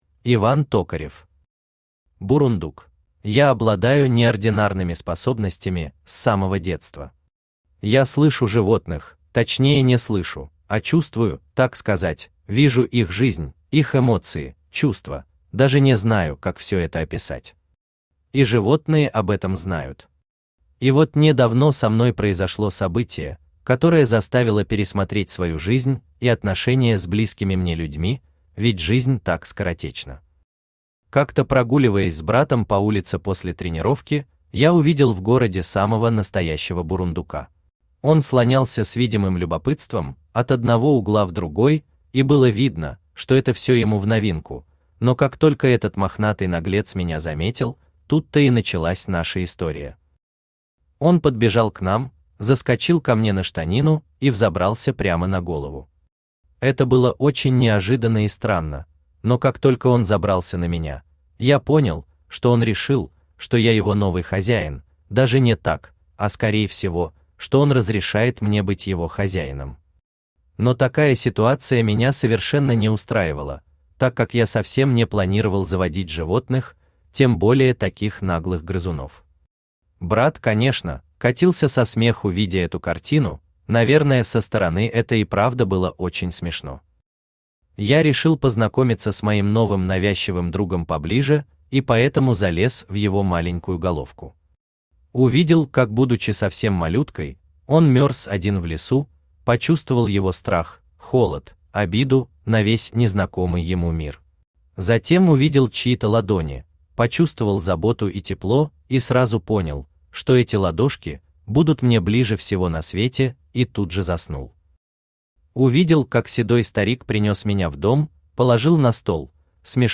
Aудиокнига Бурундук Автор Иван Токарев Читает аудиокнигу Авточтец ЛитРес.